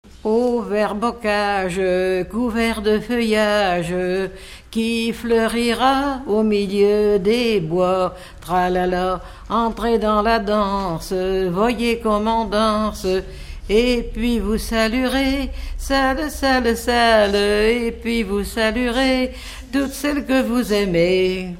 Mémoires et Patrimoines vivants - RaddO est une base de données d'archives iconographiques et sonores.
danse : ronde à saluer
Pièce musicale inédite